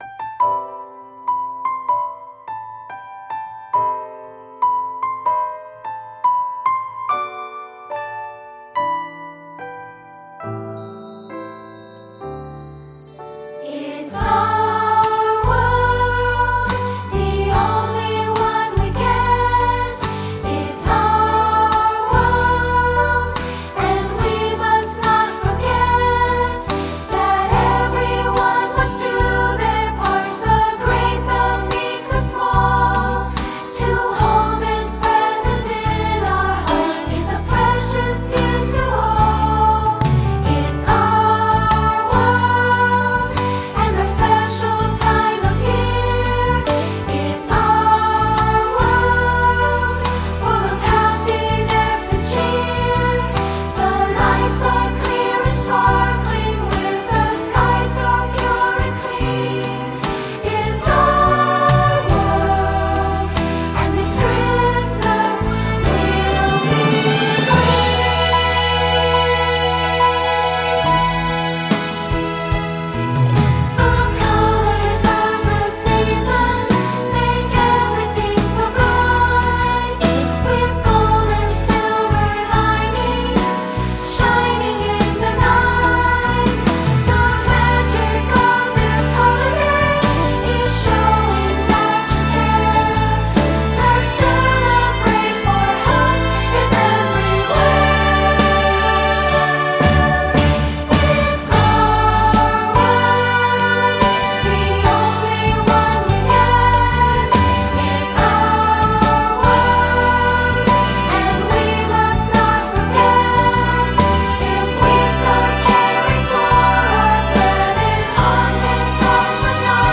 Mp3 with singing and dialogue: